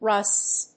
/rʌsts(米国英語)/